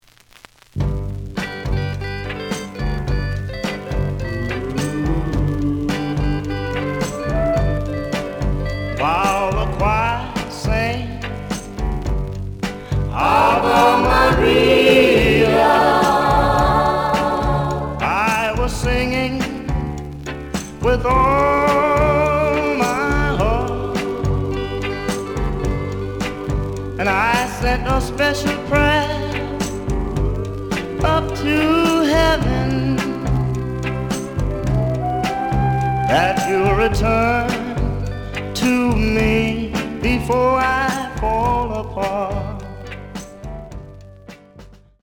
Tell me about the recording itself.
The audio sample is recorded from the actual item. Looks good, but A side has slight noise.